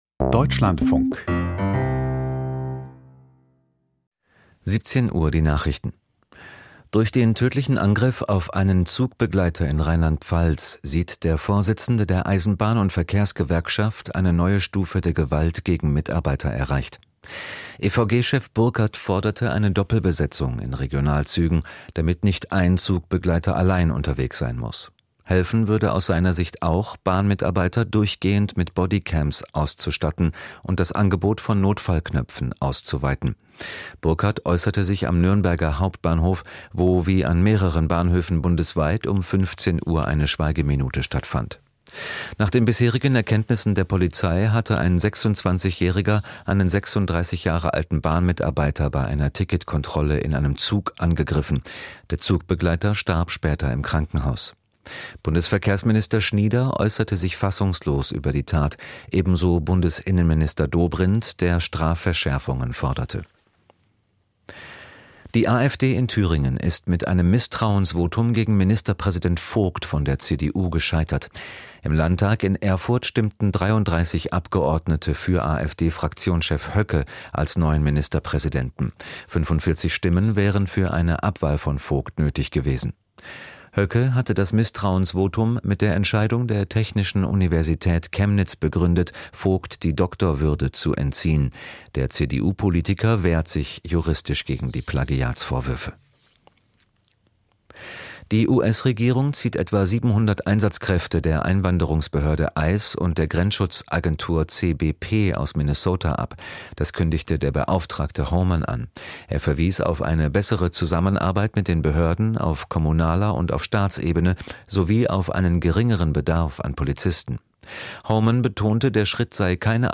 Die Nachrichten vom 04.02.2026, 17:00 Uhr
Aus der Deutschlandfunk-Nachrichtenredaktion.